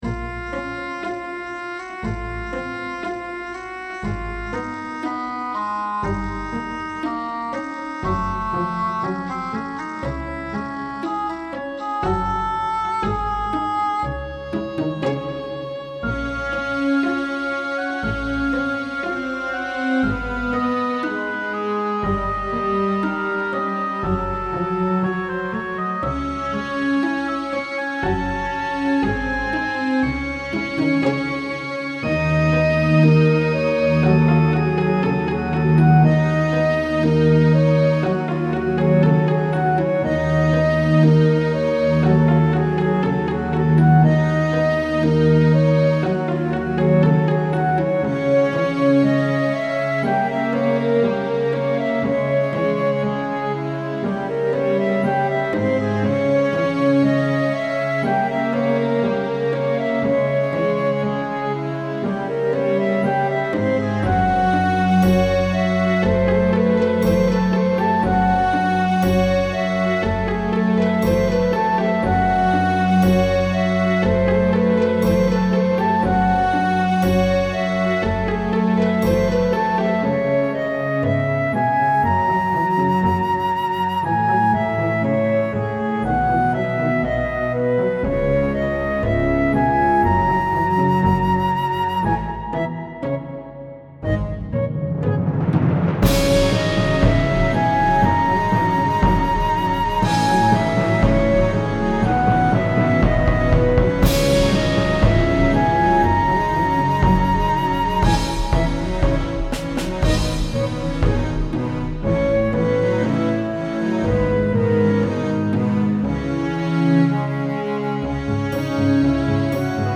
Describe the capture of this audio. A piece I made on midi, transported to Reason.